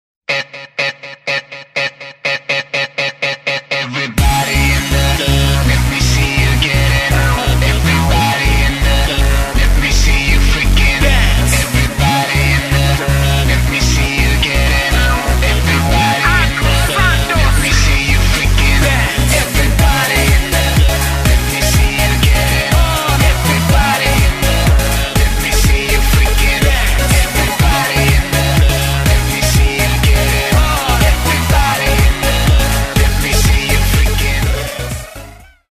мужской вокал
громкие
Хип-хоп
зажигательные
dance
EDM
энергичные
RnB
Electropop
UK garage